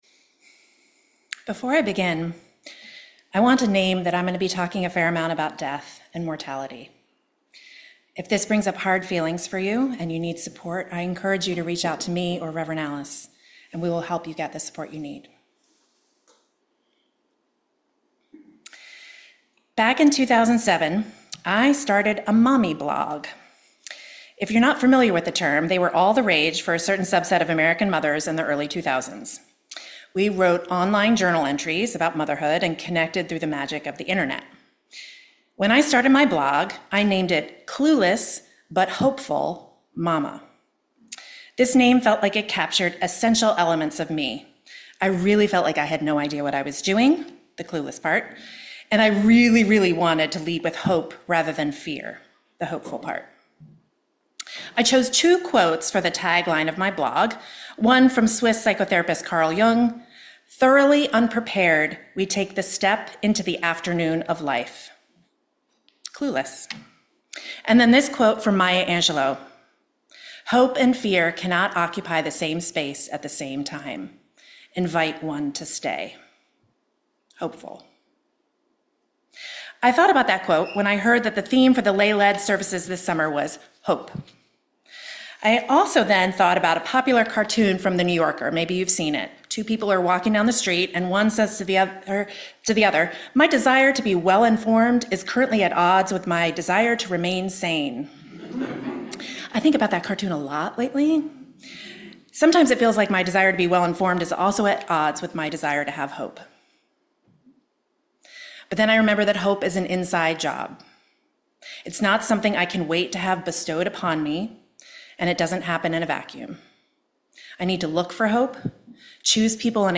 This sermon highlights the Date with Death Club, a specialized curriculum designed to help individuals confront their mortality through a supportive community lens.